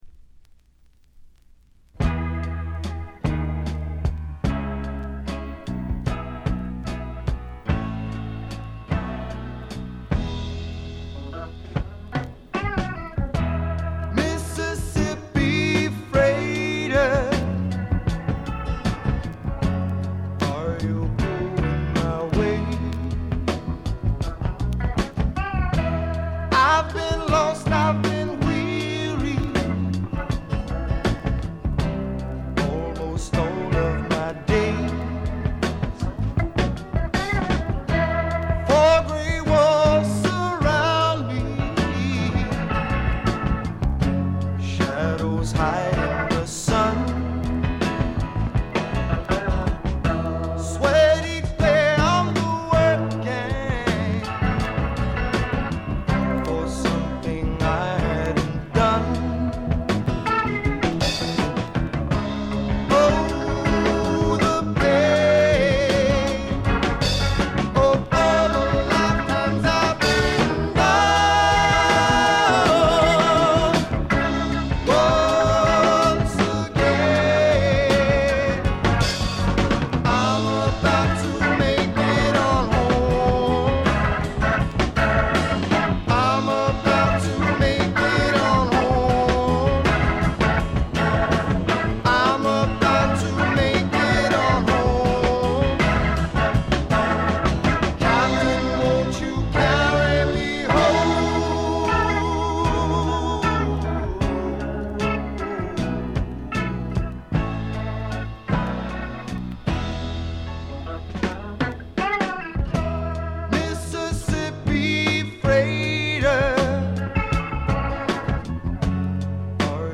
で、内容はザ・バンドからの影響が色濃いスワンプ裏名盤であります。
試聴曲は現品からの取り込み音源です。